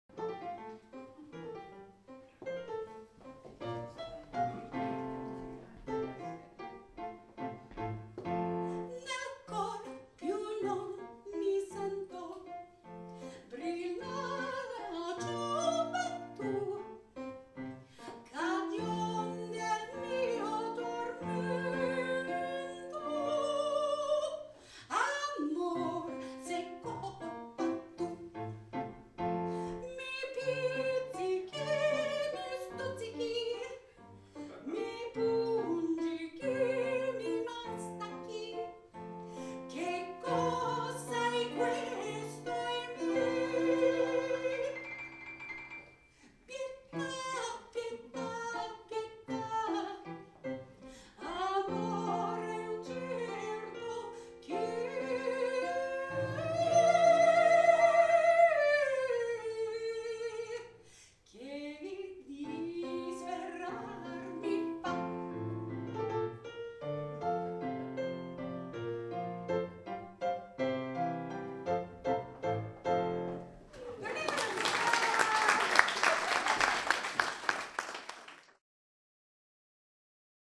singing At Last at Dazzle Jazz Club                                        Classical Italian Arias & Art Songs: